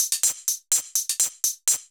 Index of /musicradar/ultimate-hihat-samples/125bpm
UHH_ElectroHatC_125-04.wav